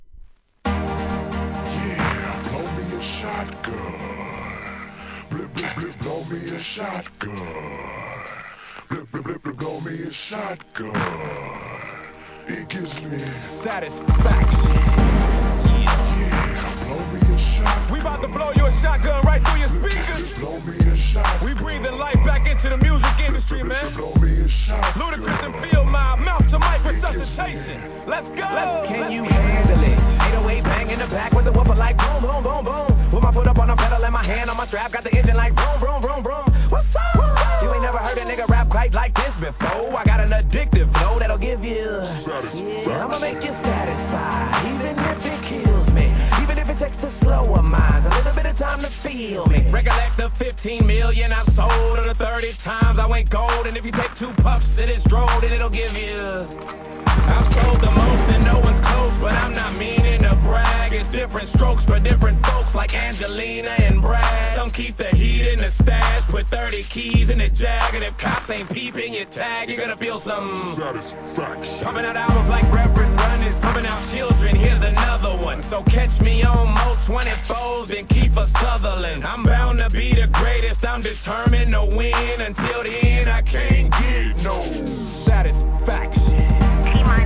HIP HOP/R&B
HOUSE〜EDM REMIX